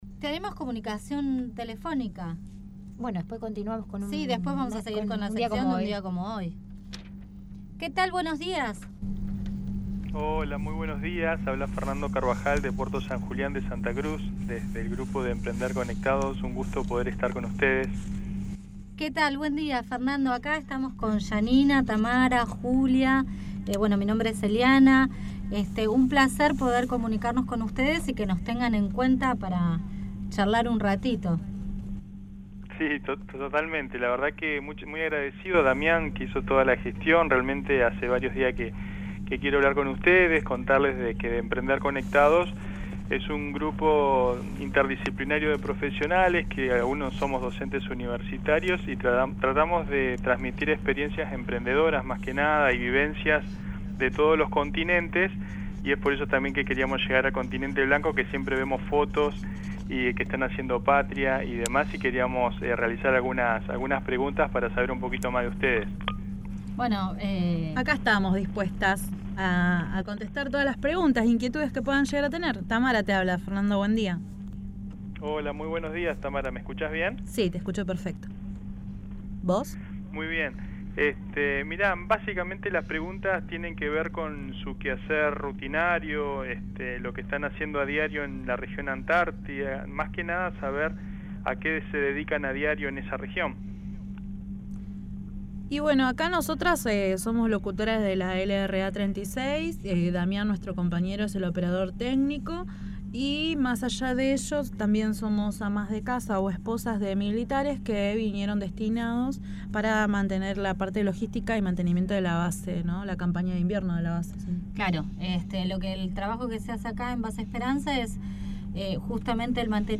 Entrevista RADIAL LRA 36 Arcángel San Gabriel